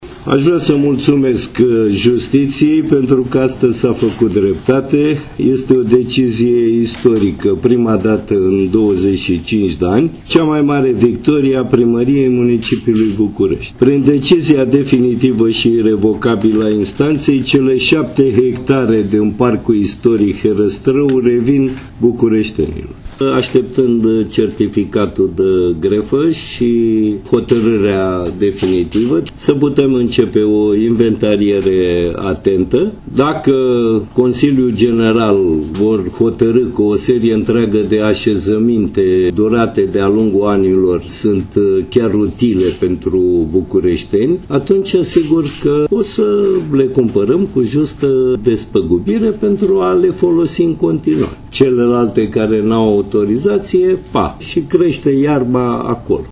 Construcțiile care au autorizații vor fi evaluate şi răscumpărate de Primăria Capitalei, în condiţţile în care Consiliul General al Municipiului Bucureşti le va considera utile pentru bucureşteni, a explicat primarul Capitalei, Sorin Oprescu: